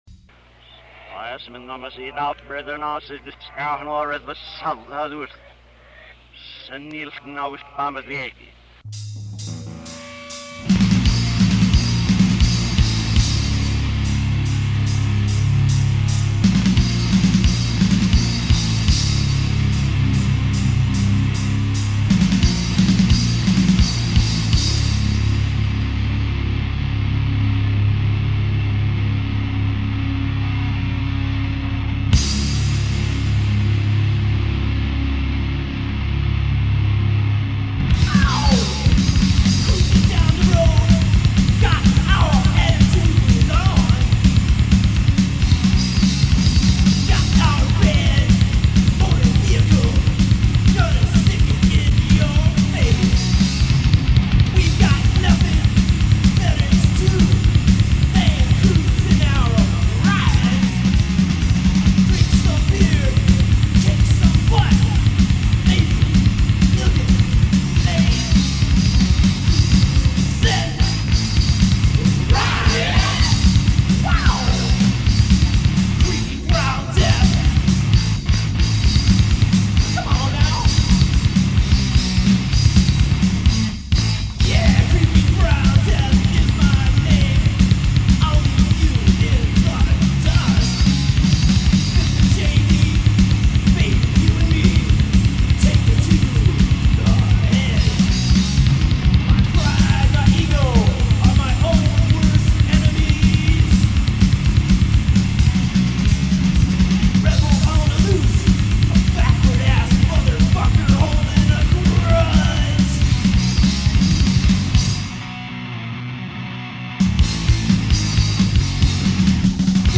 punk band